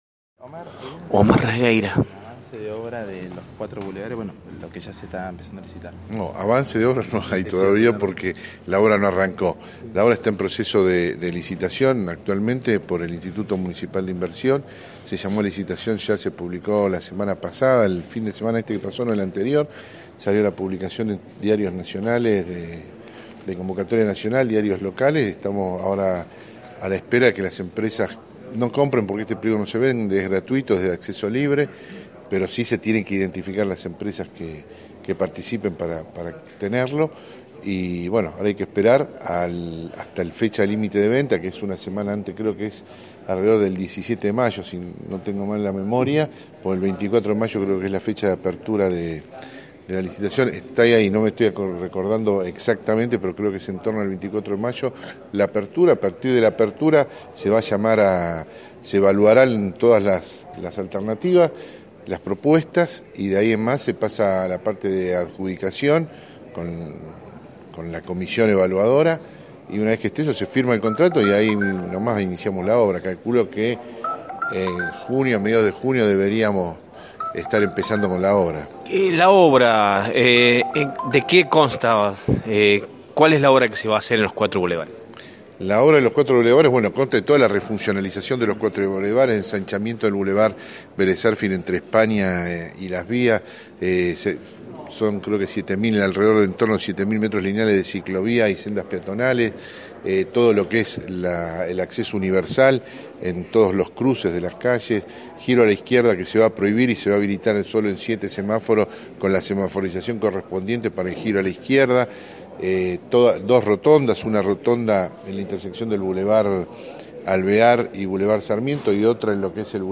El Secretario de Ambiente, Obras y Servicios Públicos, Omar Regueira, brindó detalles.